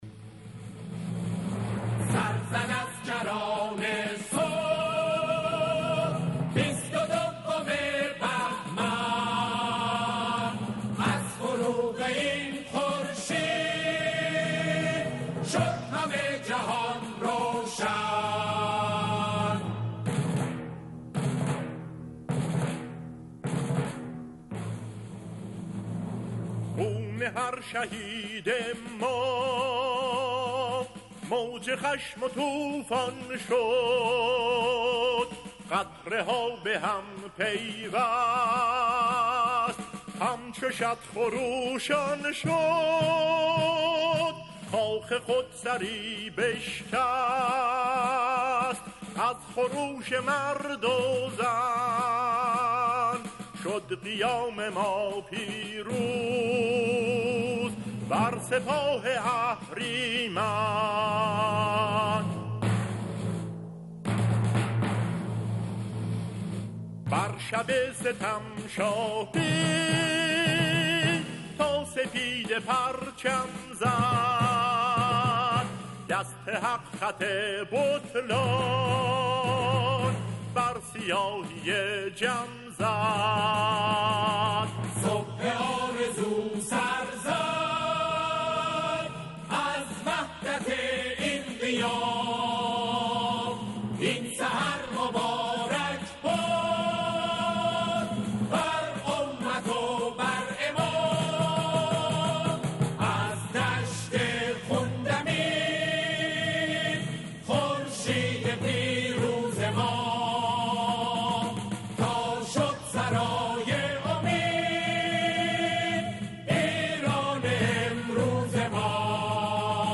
همخوانی کرده‌اند